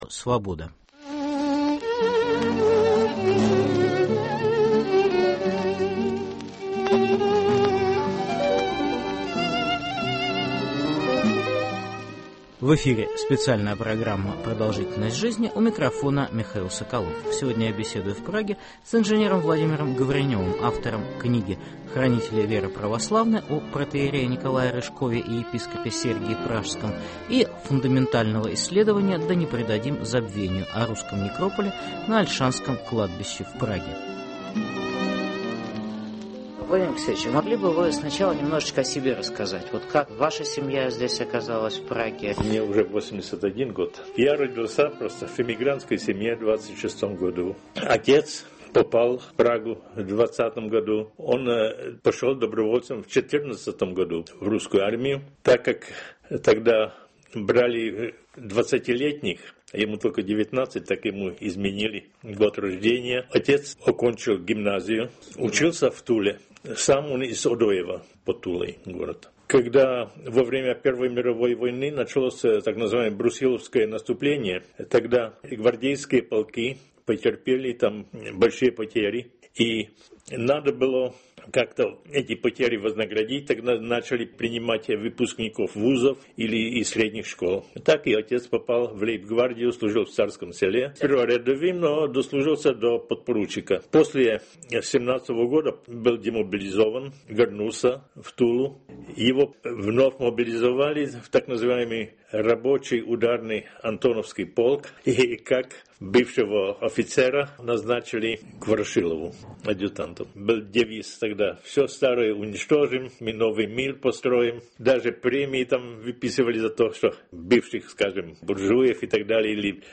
В эфире программа "Продолжительность жизни" . Церковная жизнь Русской Праги. Беседа